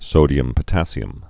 (sōdē-əm-pə-tăsē-əm)